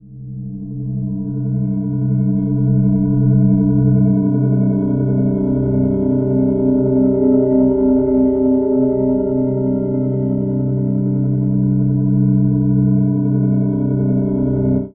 Index of /90_sSampleCDs/Chillout (ambient1&2)/13 Mystery (atmo pads)
Amb1n2_z_pad_g.wav